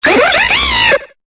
Cri de Rhinolove dans Pokémon Épée et Bouclier.